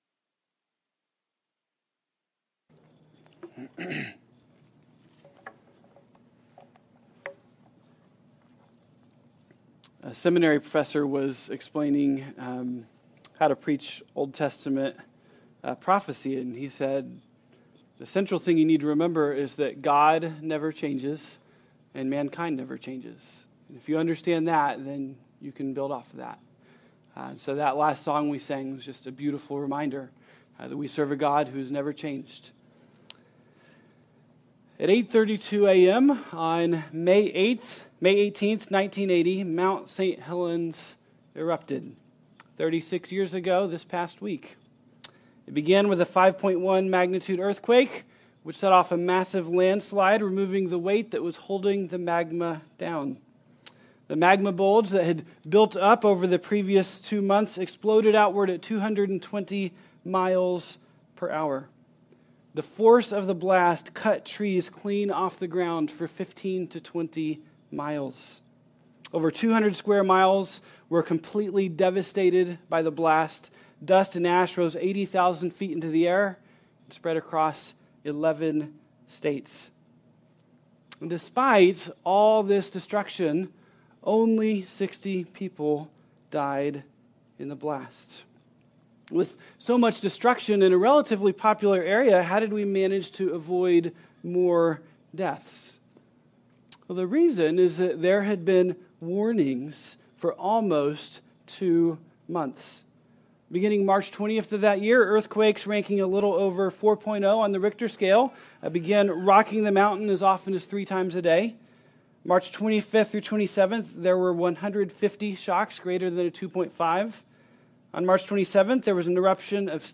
2016 ( Sunday AM ) Bible Text